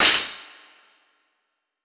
TC2 Clap10.wav